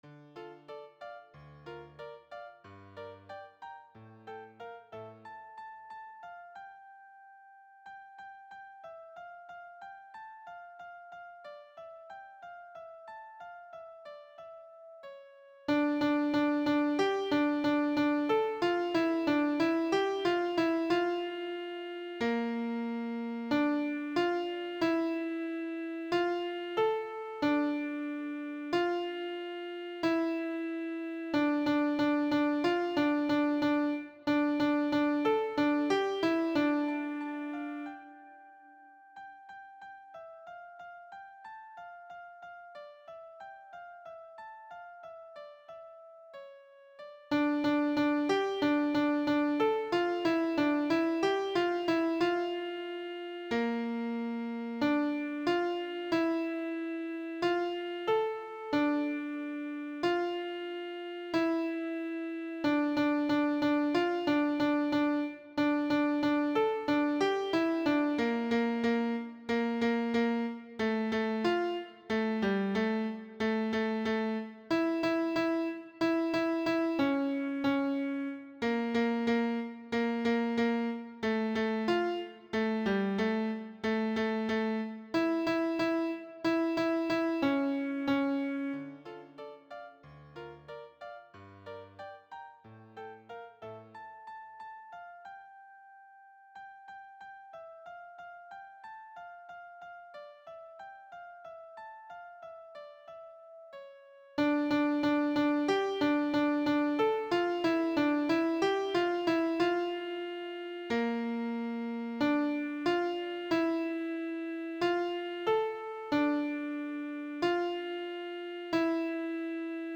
voce bassa